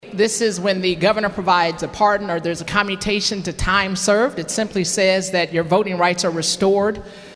CLICK HERE to listen to commentary from Representative Regina Goodwin of Tulsa.